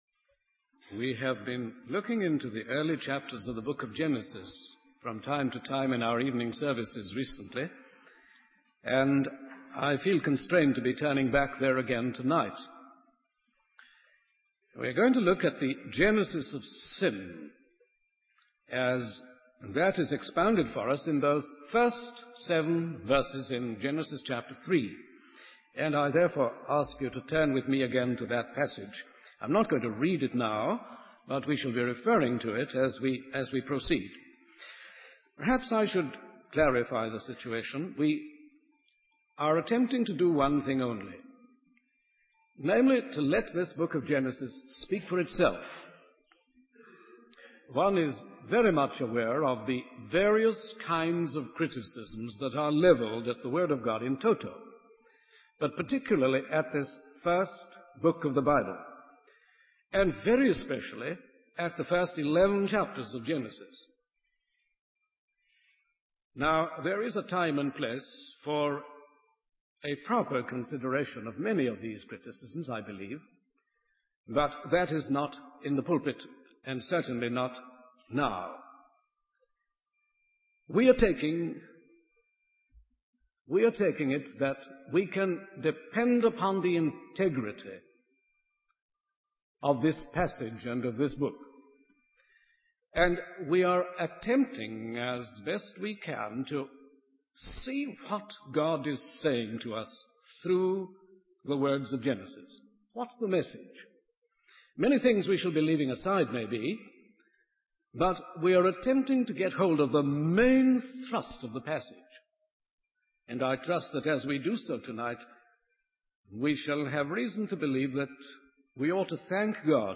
In this sermon, the preacher explains that the world is in its current state of mess and unrest because of the rebellion of mankind.